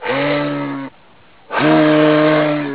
here to listen to a bull moose calling.
moose.wav